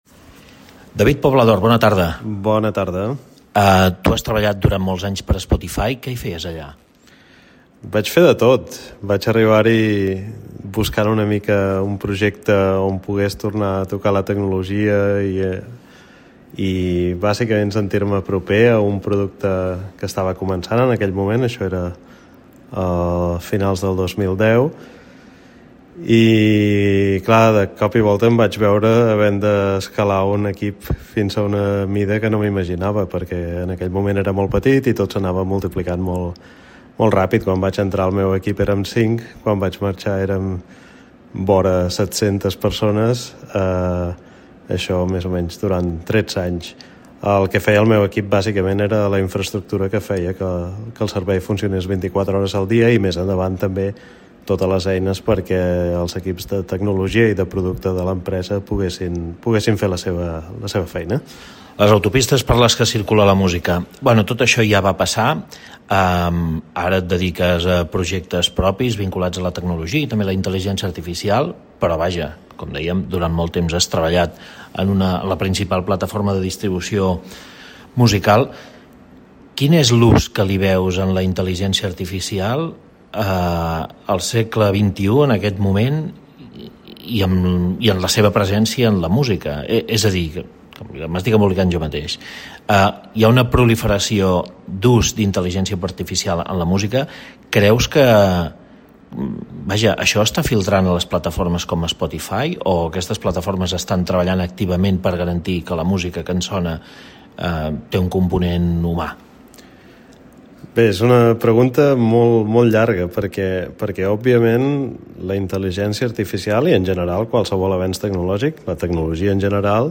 En una entrevista al programa De cap a cap de Ràdio Capital, defensa que la IA pot ajudar les llengües minoritàries a guanyar visibilitat, tot i advertir dels riscos d’extractivisme cultural i d’una pèrdua de control dels continguts.